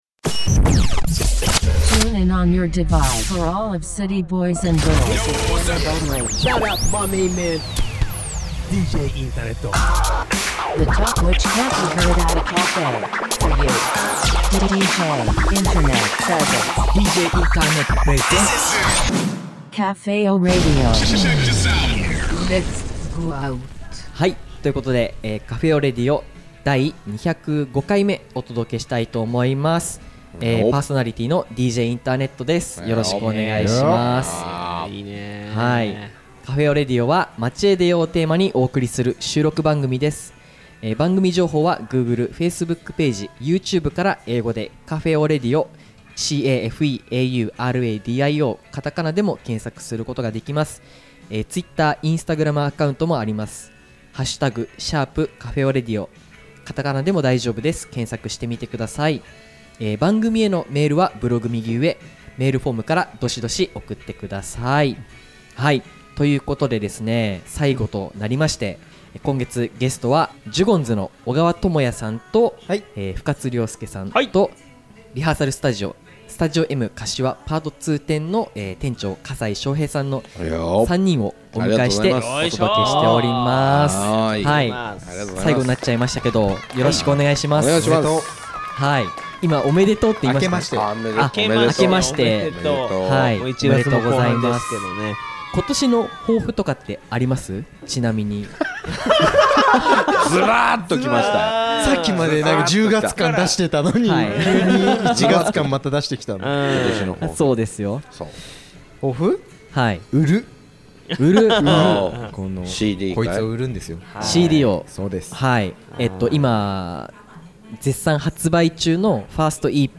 もちろんニューリリースのEPからも1曲オンエアしています！！